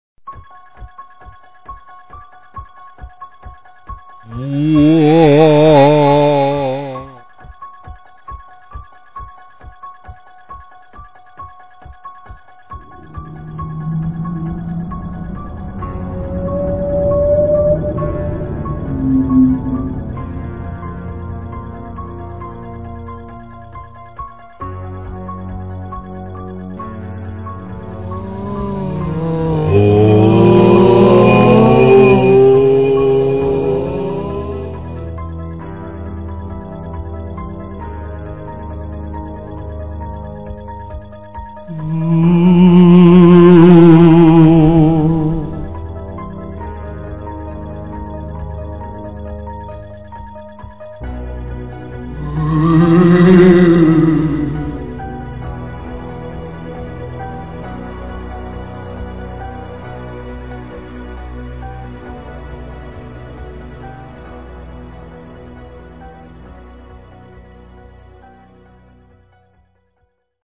Geïnteresseerd in de superangstaanjagende spookgeluiden op de achtergrond?